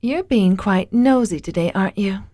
Nadine is very pretty, and she knows it. She always talks with confidence and a tone of arrogance.